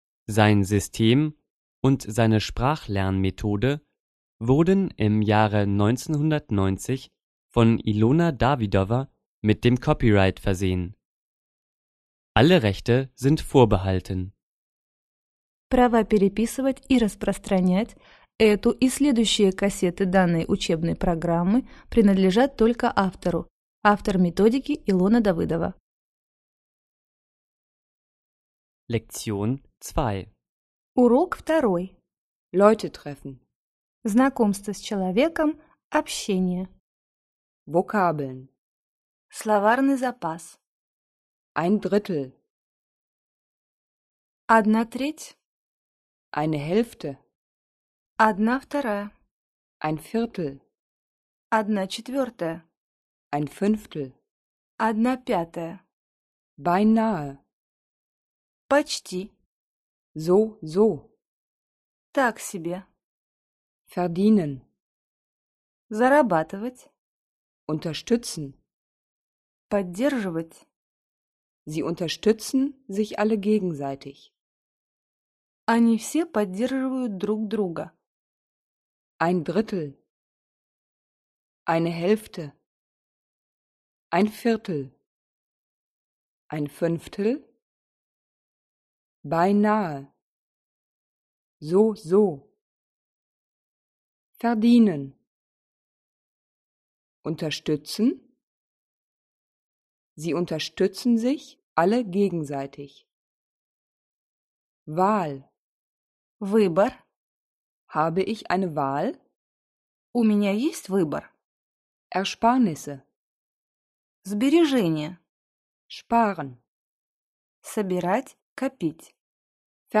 Аудиокнига Разговорно-бытовой немецкий язык. Курс 1. Диск 2 | Библиотека аудиокниг